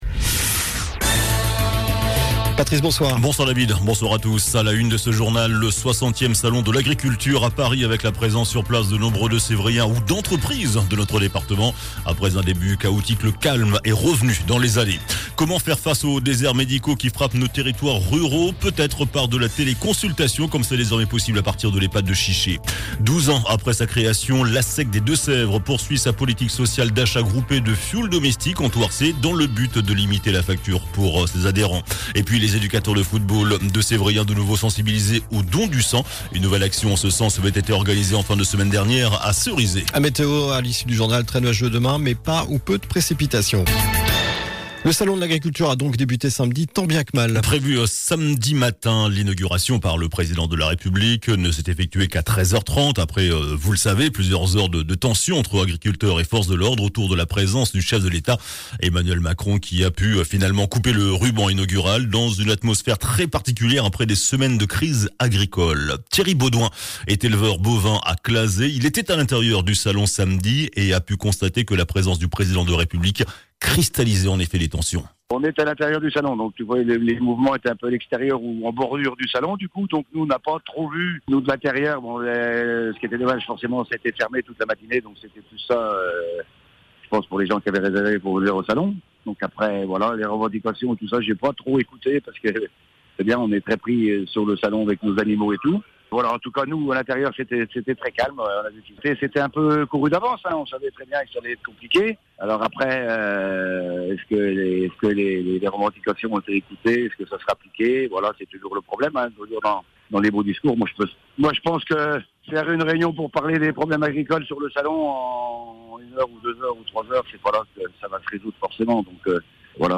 JOURNAL DU LUNDI 26 FEVRIER ( SOIR )